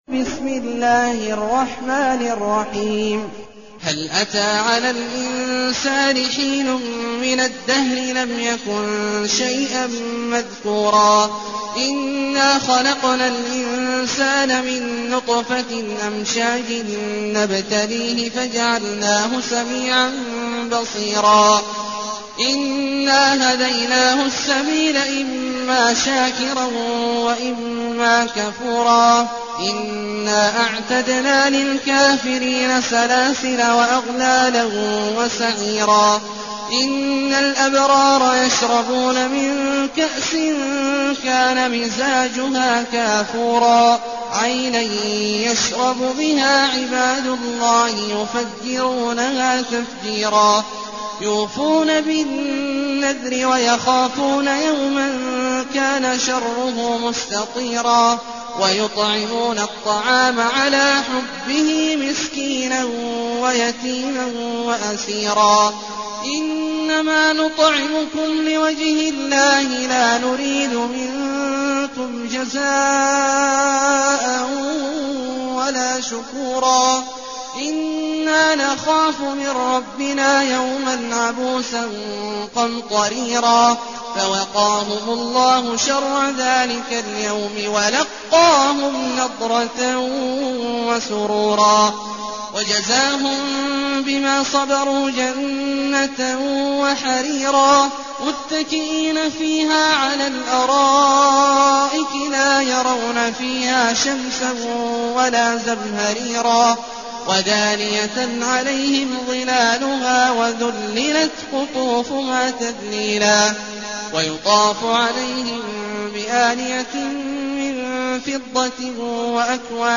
المكان: المسجد النبوي الشيخ: فضيلة الشيخ عبدالله الجهني فضيلة الشيخ عبدالله الجهني الإنسان